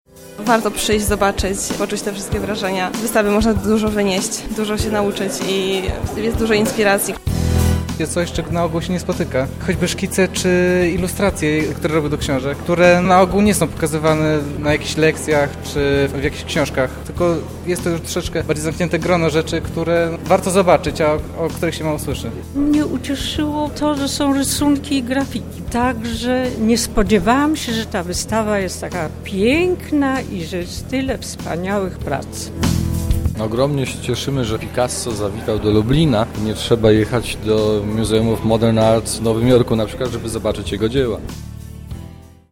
Podczas piątkowego wernisażu odwiedzający nie kryją zadowolenia z przyjazdu prac.